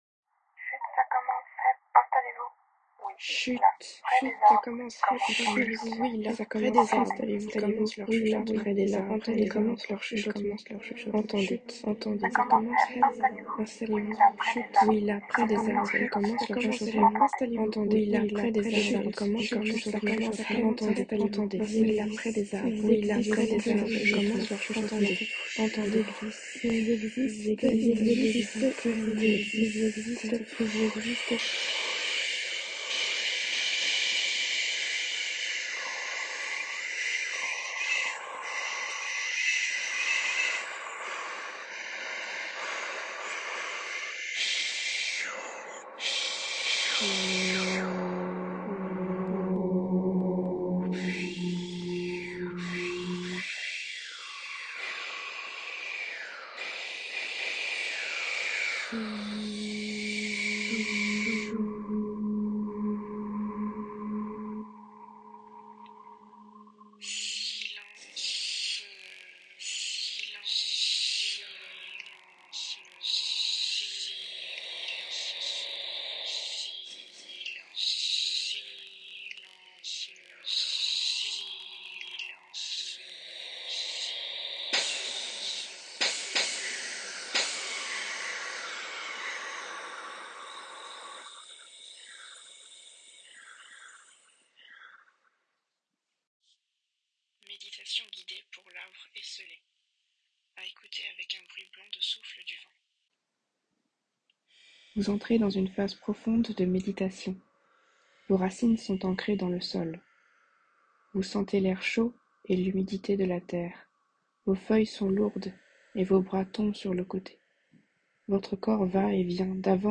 Livret Poèmes parlés et dessinés ￼